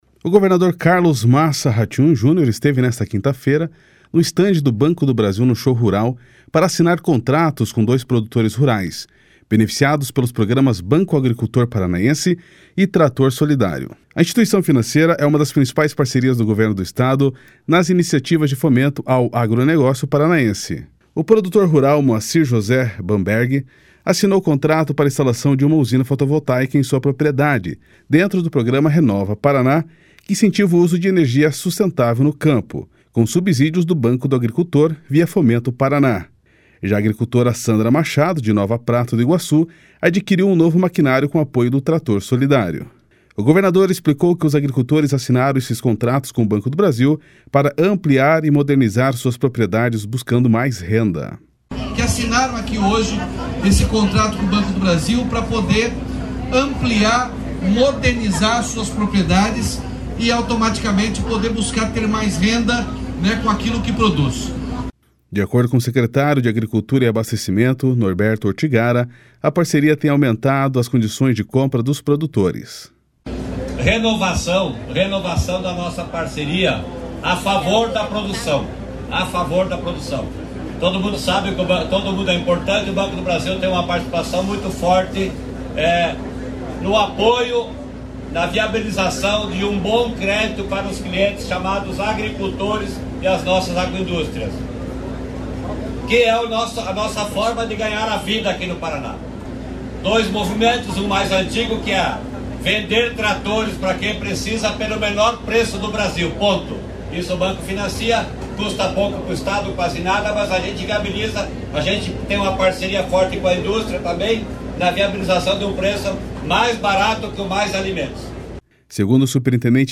//SONORA NORBERTO ORTIGARA//